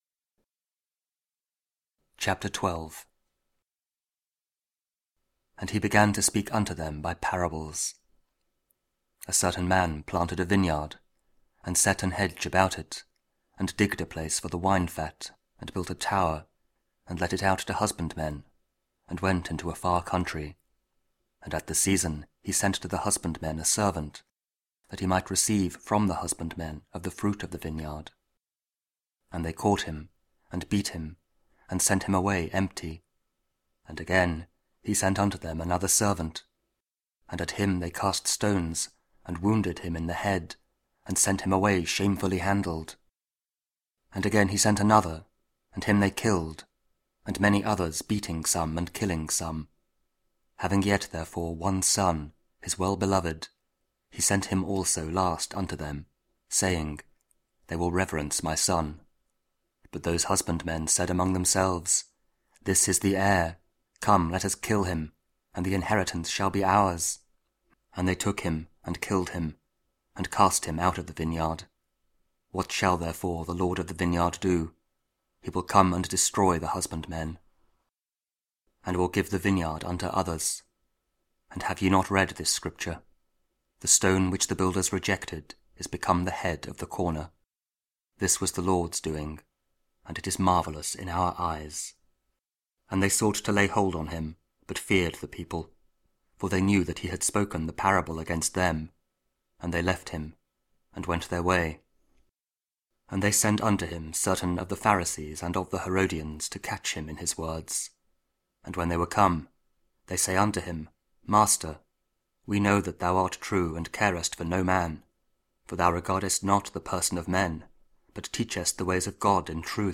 Mark 12 (Audio Bible KJV, Spoken Word)